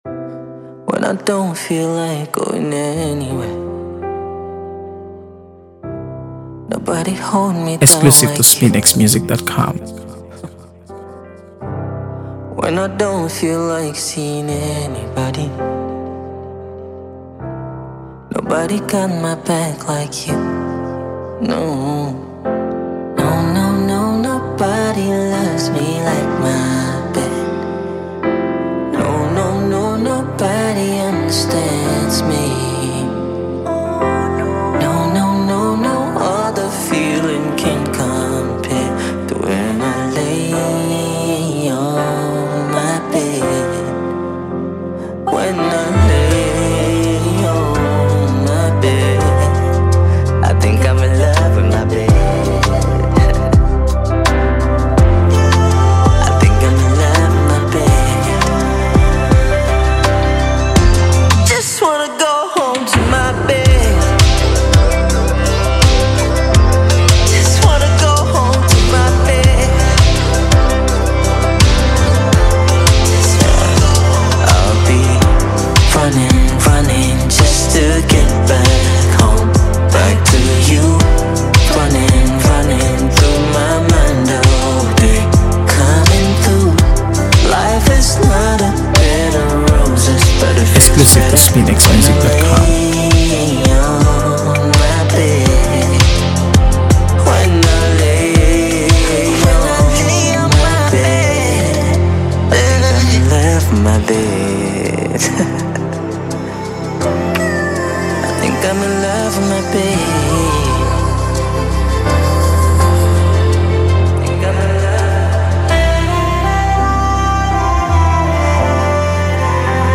AfroBeats | AfroBeats songs
soulful melodies and heartfelt lyrics